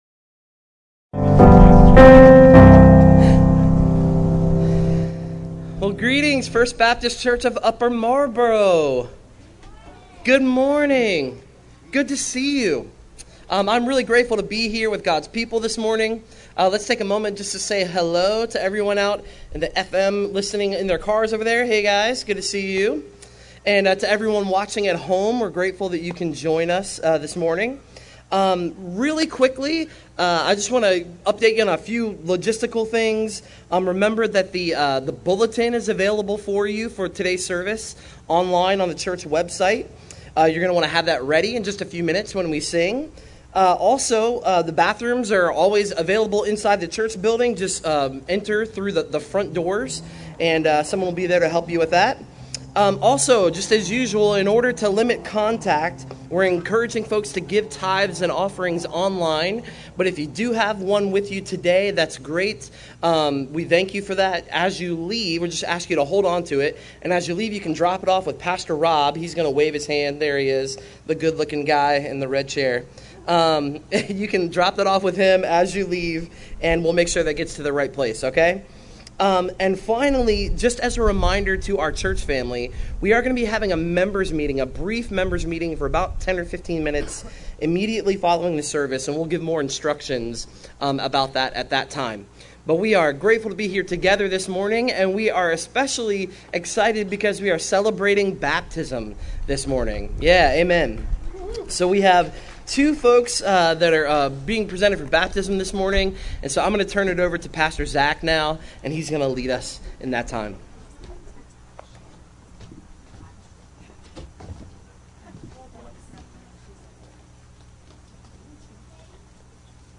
James shows us how a Christ follower responds to God’s word Sermon Outline I. Hear God’s word with an attentive heart (1:19-20) II.